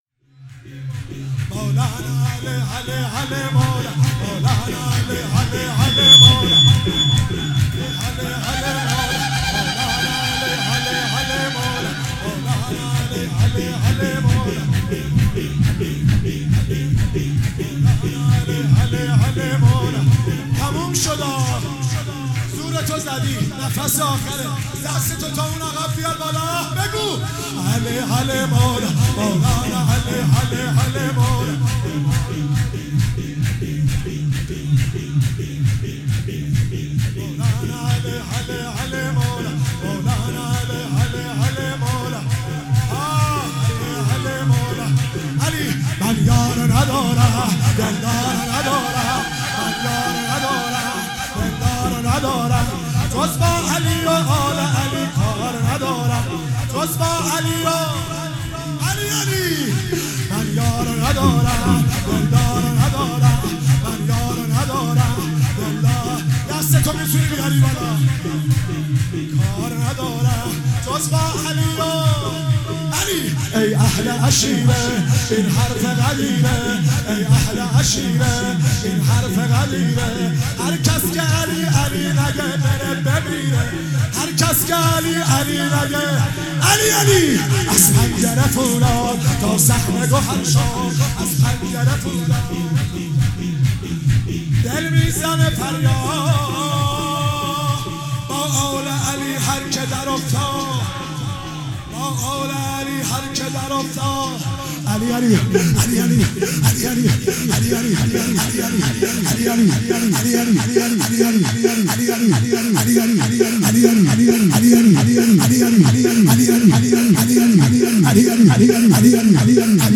هفتگی 19 آذر_ شور _ علی علی مولا